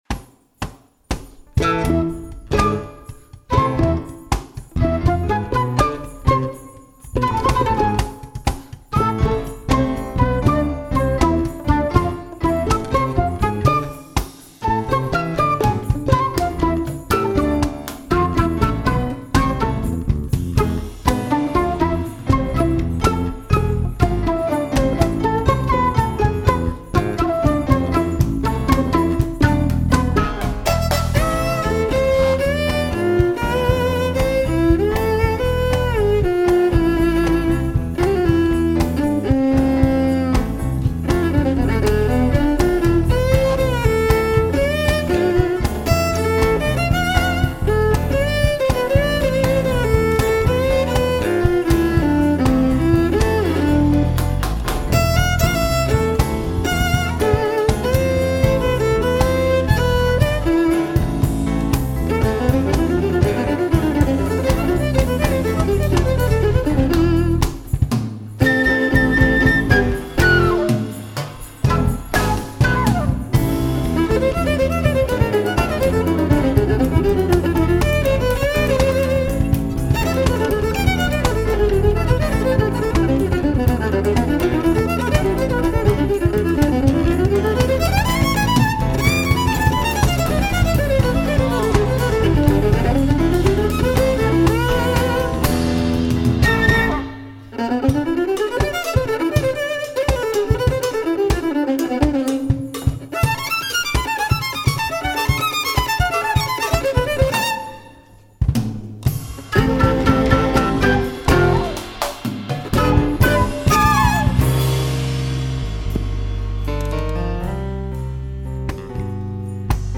Música popular: instrumental y jazz